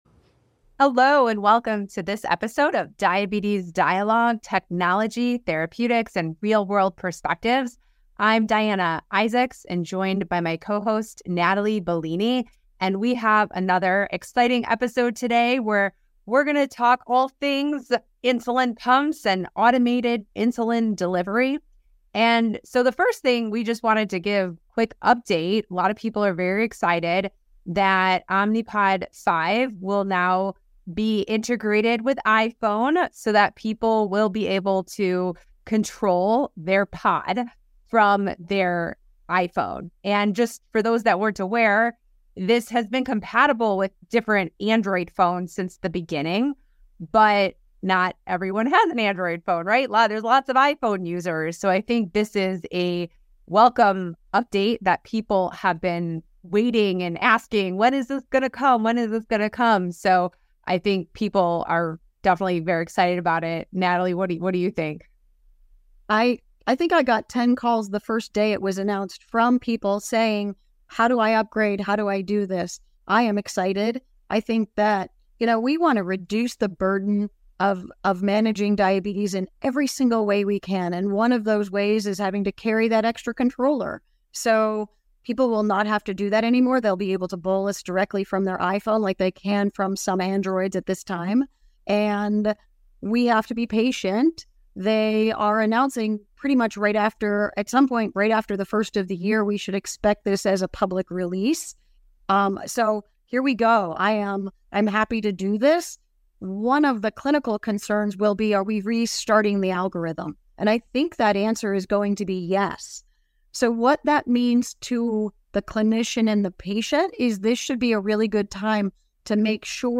In this episode, hosts discuss the FDA clearance of the Omnipod 5 App for iPhones and a pair of studies examining use of automated insulin delivery systems.